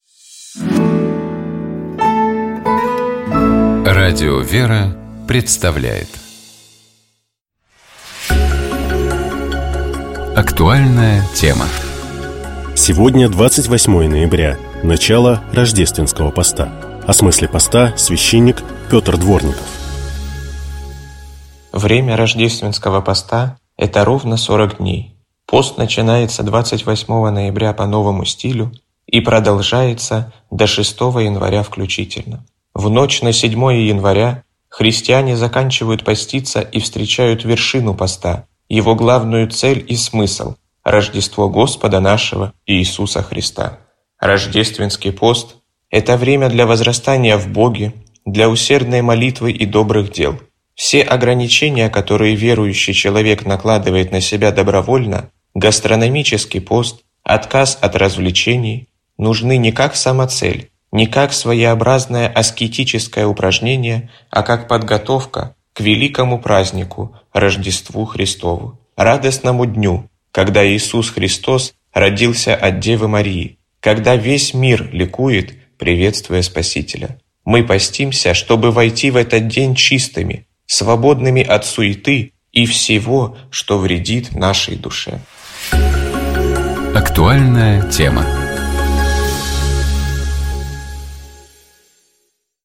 Гость программы — Владимир Легойда, председатель Синодального отдела по взаимоотношениям Церкви с обществом и СМИ, член Общественной палаты РФ.